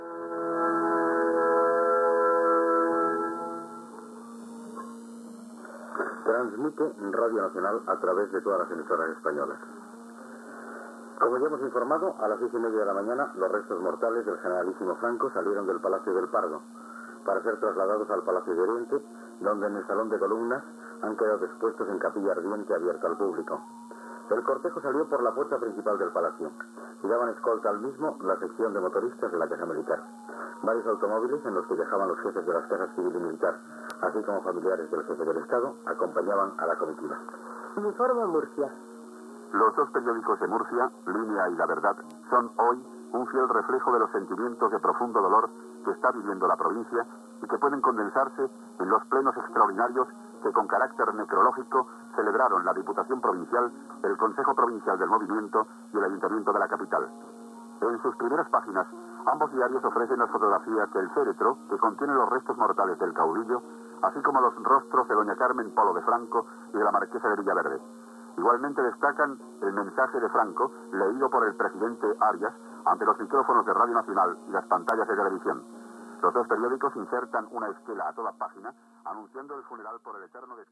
Indicatiu de RNE amb connexió amb totes les emissores espanyoles. Dia després de la mort del cap d'Estat, el "generalísimo" Francisco Franco.
Informatiu